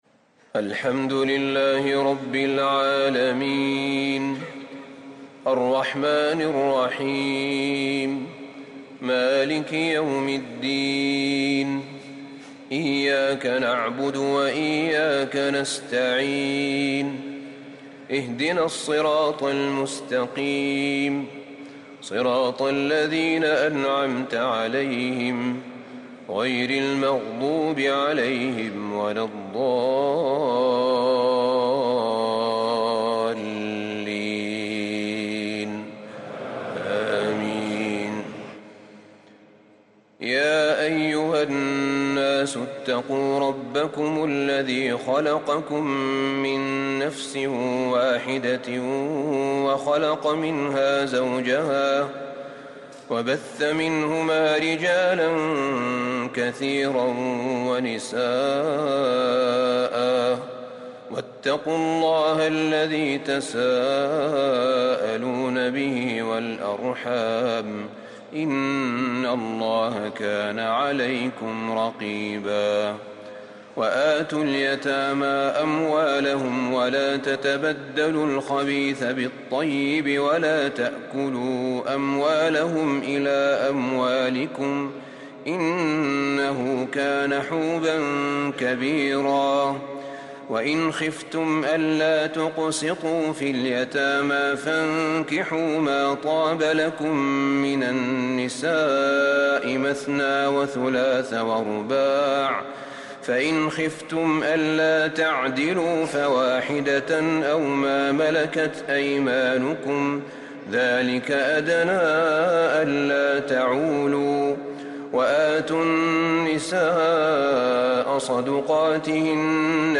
تراويح ليلة 6 رمضان 1444هـ فواتح سورة النساء (1-42) | Taraweeh 6st night Ramadan 1444H Surah An-Nisaa > تراويح الحرم النبوي عام 1444 🕌 > التراويح - تلاوات الحرمين